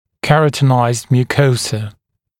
[‘kerətənaɪzd mjuː’kəusə][‘кэрэтэнайзд мйу:’коусэ]кератинизированная слизистая оболочка